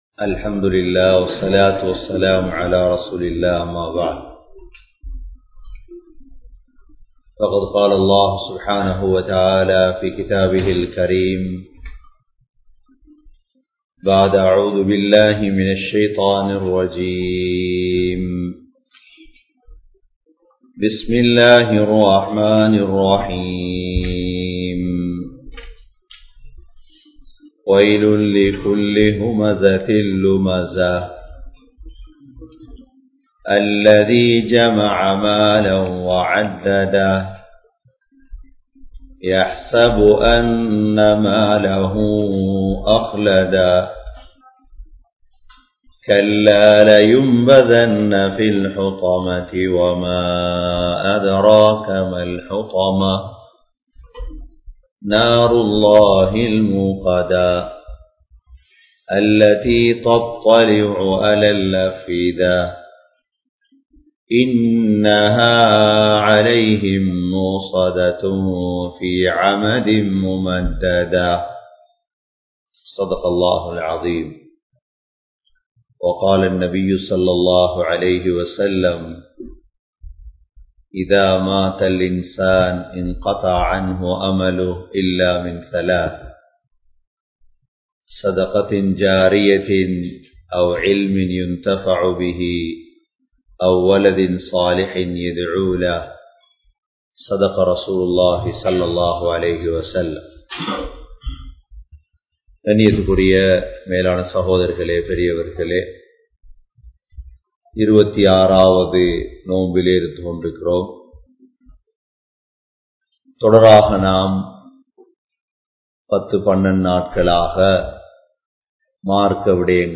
Pinpatra Vendiya Moonru Vidayangal!(பின்பற்ற வேண்டிய மூன்று விடயங்கள்!) | Audio Bayans | All Ceylon Muslim Youth Community | Addalaichenai
Canada, Toronto, Thaqwa Masjidh